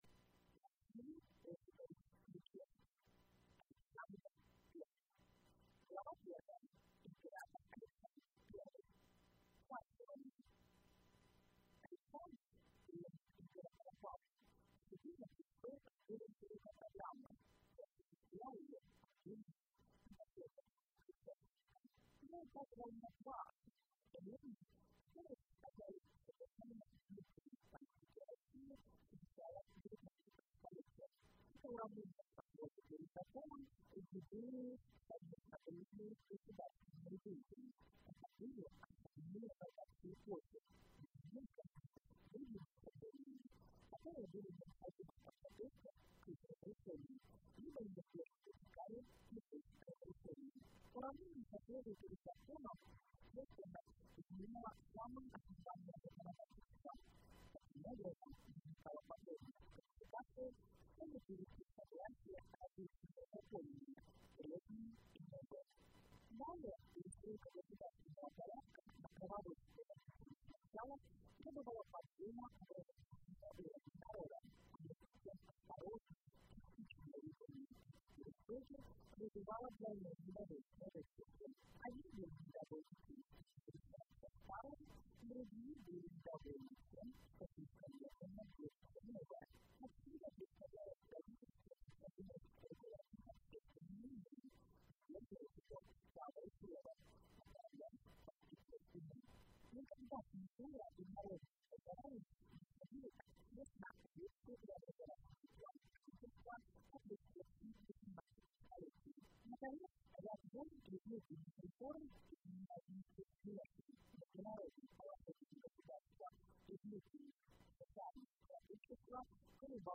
Аудиокнига Александр I | Библиотека аудиокниг